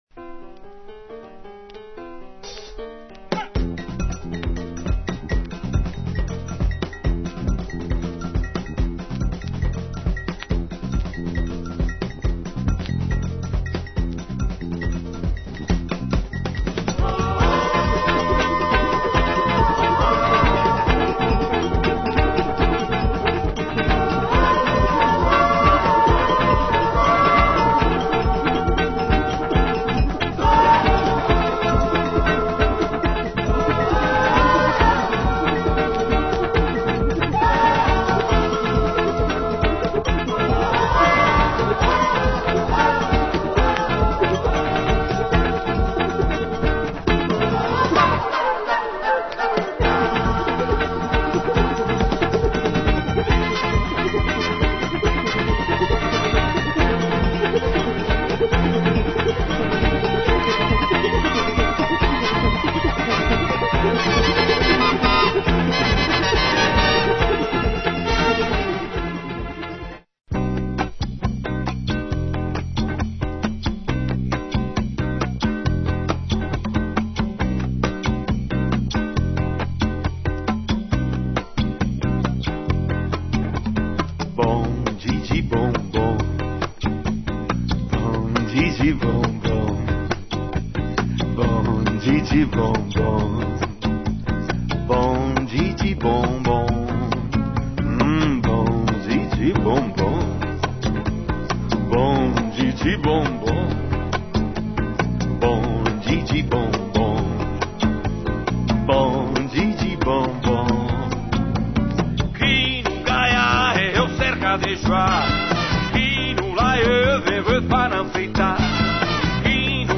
A beautiful album with Brazilian flavour
wicked latin & jazzy groove
bossa nova